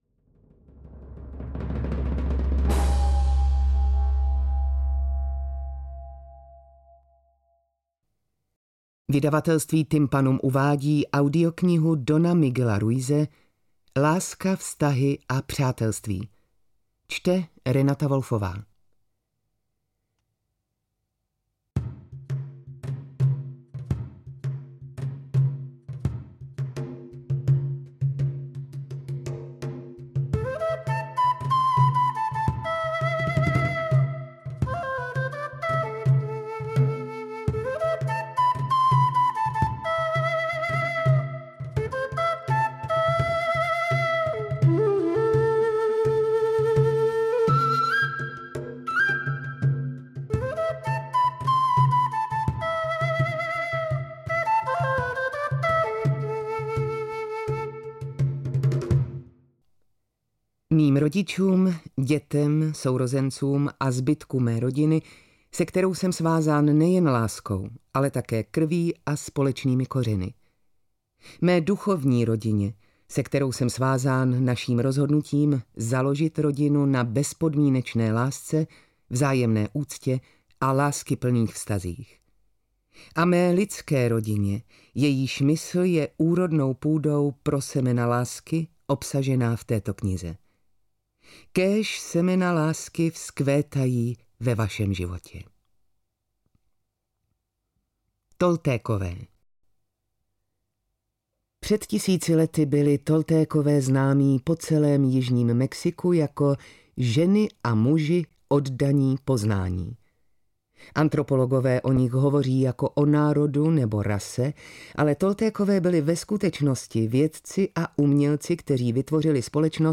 AudioKniha ke stažení, 21 x mp3, délka 4 hod. 4 min., velikost 223,3 MB, česky